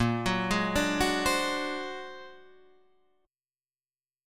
Bb+9 Chord
Listen to Bb+9 strummed